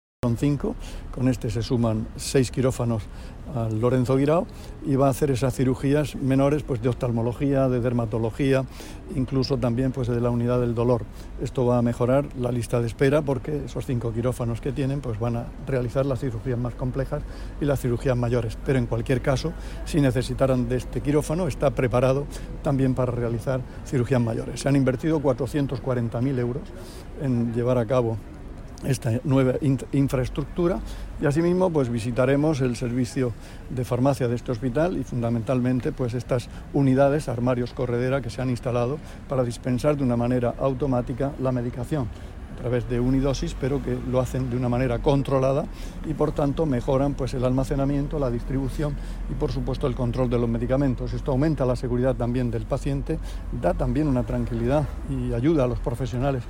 Declaraciones del consejero de Salud sobre las mejoras en el hospital' Lorenzo Guirao' de Cieza.